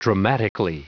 Prononciation audio / Fichier audio de DRAMATICALLY en anglais
Prononciation du mot : dramatically